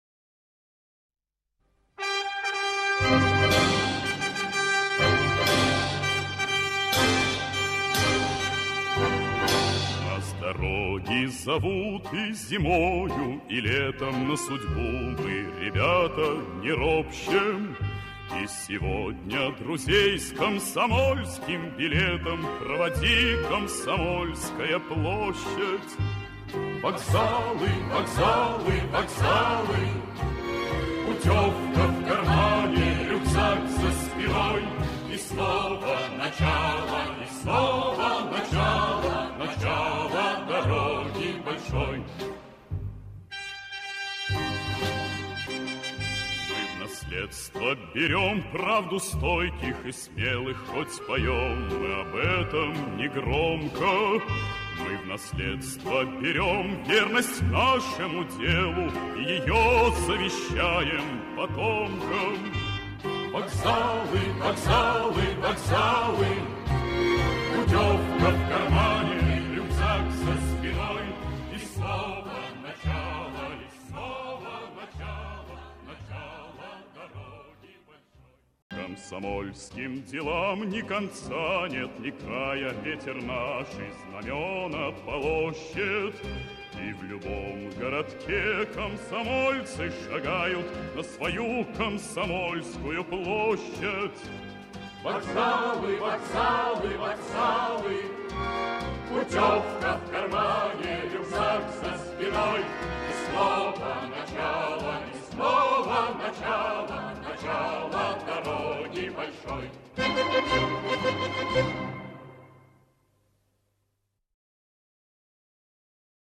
3 куплет песни в радиоспектакле разнится с оригиналом